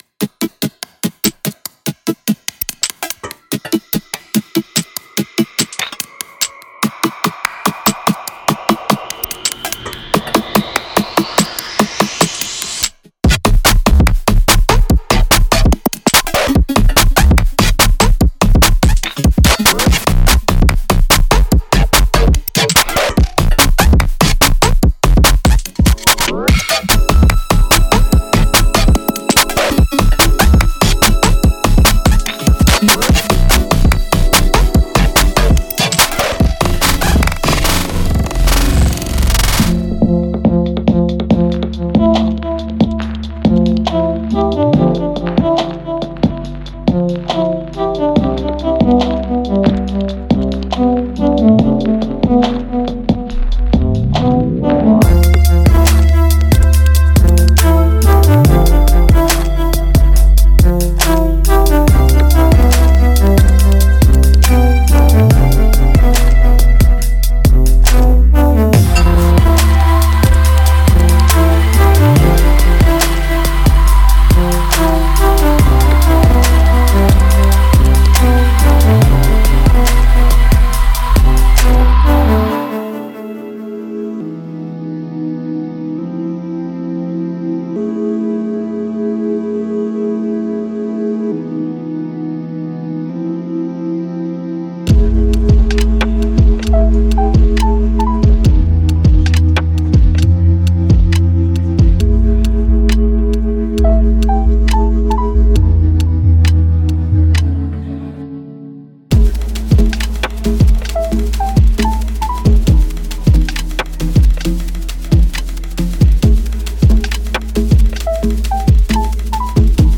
令人难以置信的FX和这个泛类型合集中详细的合成器满足了广阔的沉思垫。